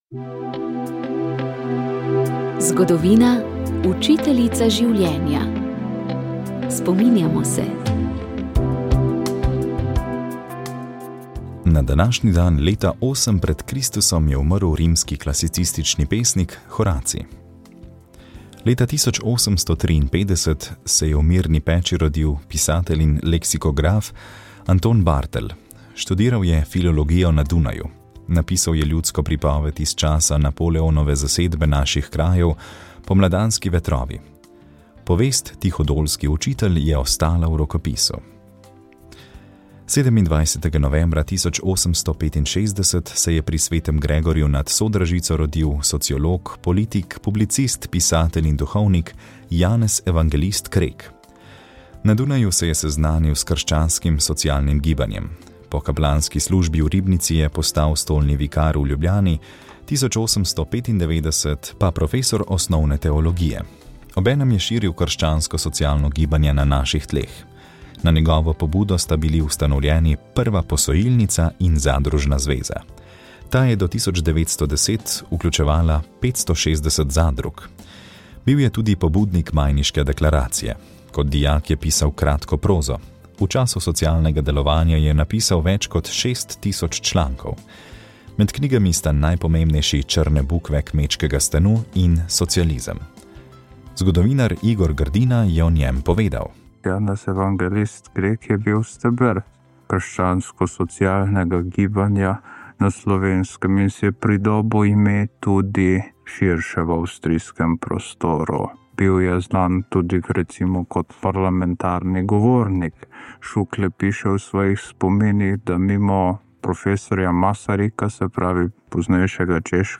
V pogovoru z njim smo med drugim vstopili v ozadje vse večjega števila partnerskih in zakonskih razhodov.